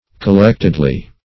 Collectedly \Col*lect"ed*ly\, adv.